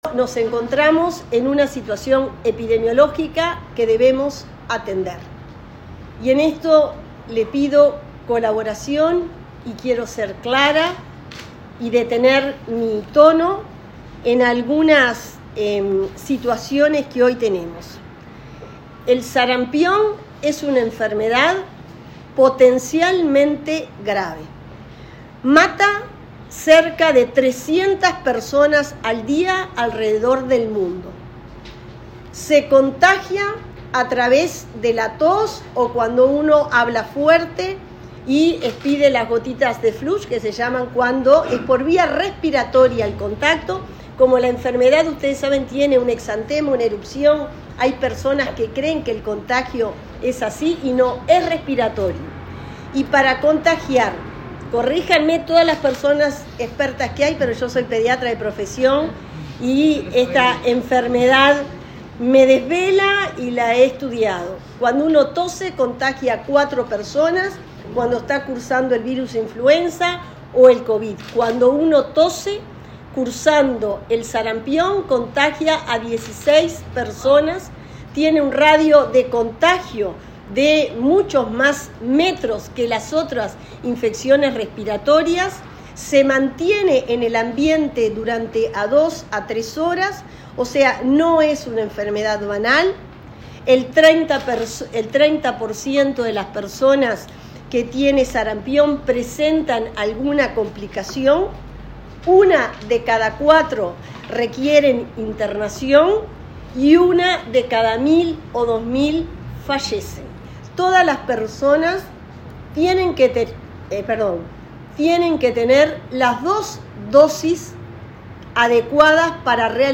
Declaraciones de la ministra de Salud Pública, Cristina Lustemberg
En conferencia de prensa, la ministra de Salud Pública, informó sobre el estado de situación epidemiológica del sarampión en Uruguay, y recomendó que